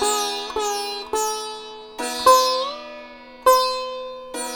105-SITAR2-R.wav